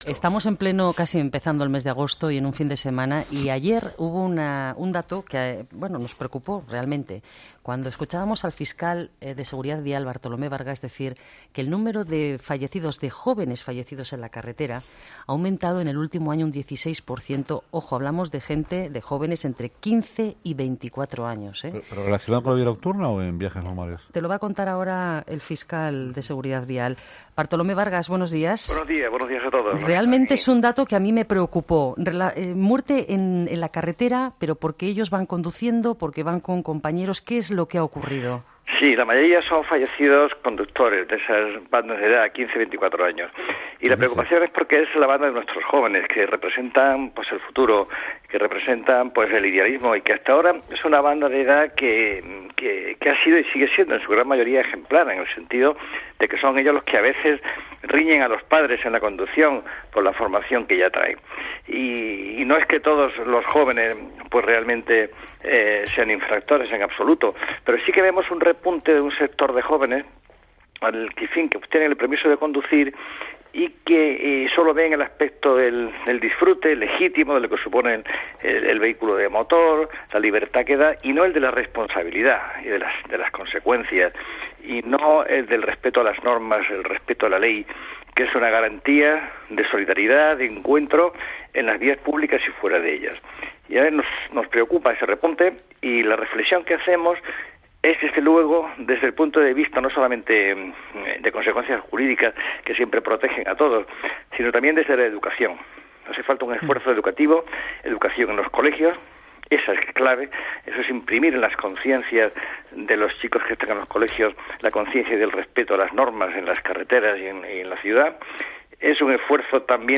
ESCUCHA LA ENTREVISTA COMPLETA | Bartolomé Vargas, El fiscal coordinador de Seguridad Vial